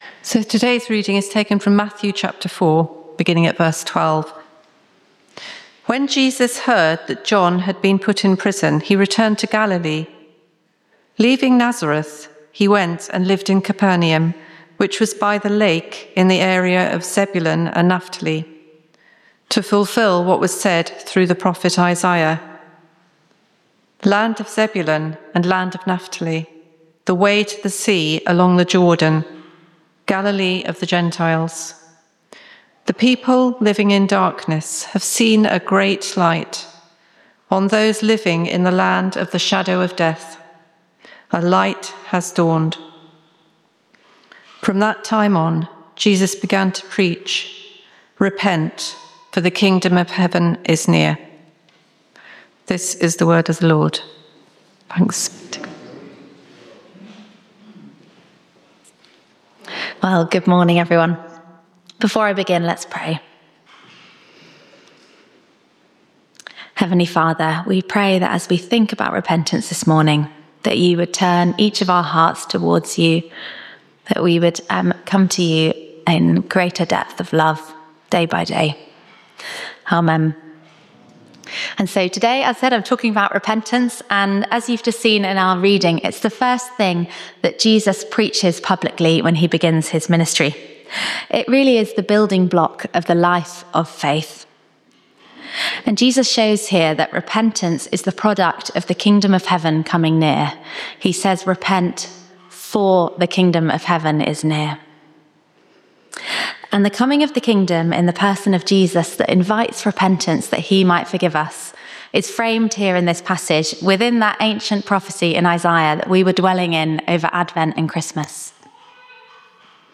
Location: St Mary’s, Slaugham Date
Service Type: Informal Praise